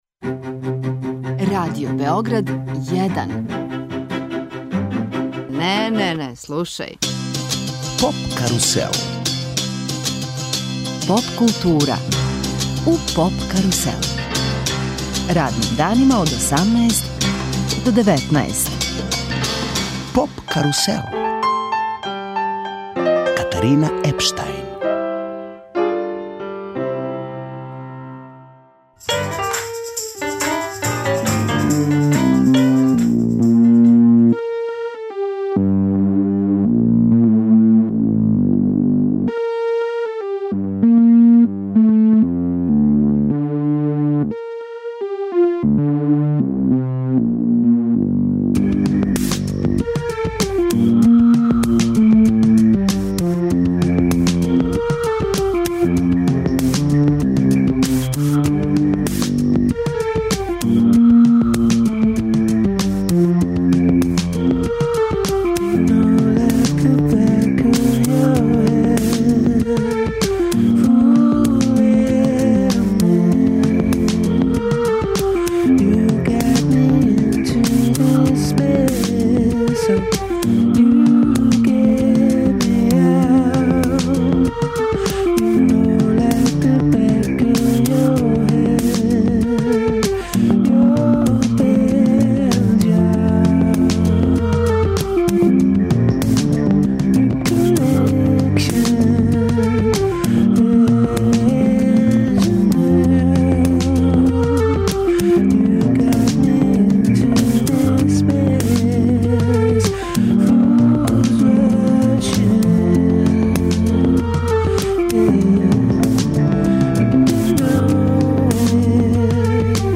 Сваког дана резимирамо претходну ноћ, слушамо извођаче и преносимо део атмосфере.